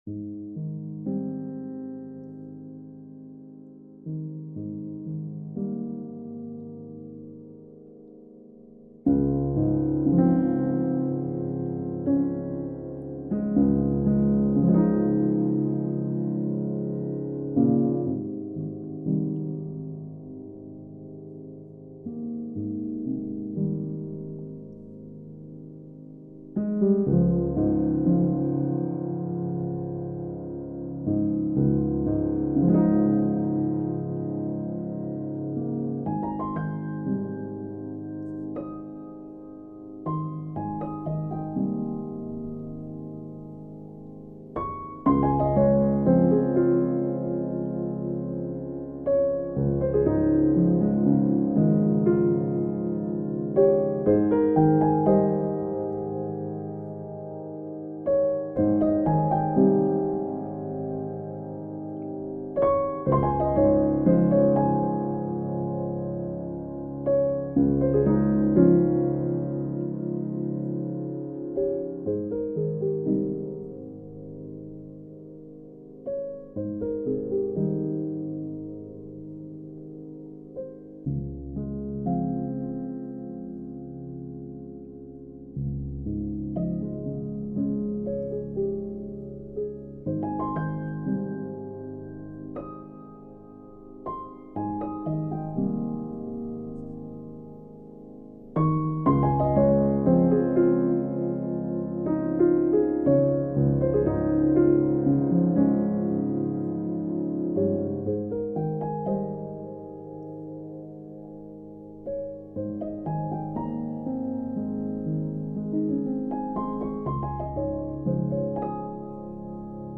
Piano Music, Solo Keyboard
Despite the simplicity, I think this one turned out absolutely awesome. it was inspired by some... for lack of better words... interestingly put together scoring done by a novice user on the Musescore platform. The only thing that is anywhere near the same is the feel I thought he was goin for, and the key of Ab Major being used recently updated the key signature of this piece to Bb major, with emphasis on the Major 7ths.